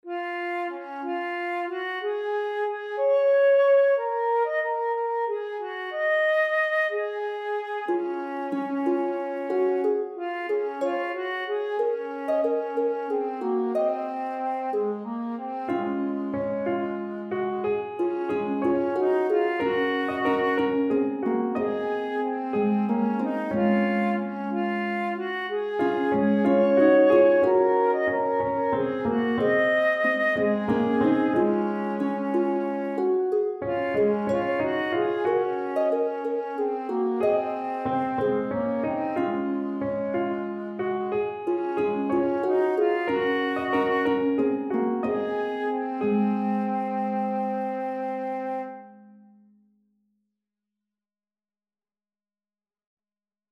A round for 3 voices
Als driestemmige canon